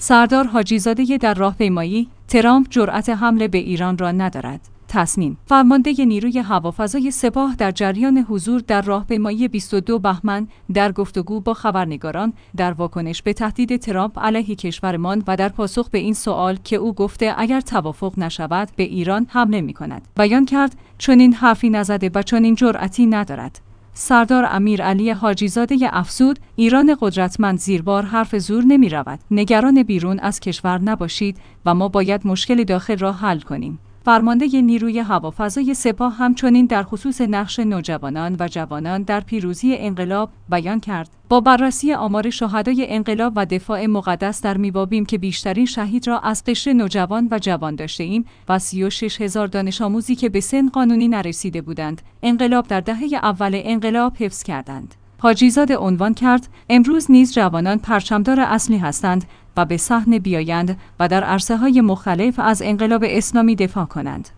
تسنیم/ فرمانده نیروی هوافضای سپاه در جریان حضور در راهپیمایی 22 بهمن، در گفت‌وگو با خبرنگاران در واکنش به تهدید ترامپ علیه کشورمان و در پاسخ به این سوال که او گفته اگر توافق نشود به ایران حمله می‌کند، بیان کرد: چنین حرفی نزده و چنین جرأتی ندارد.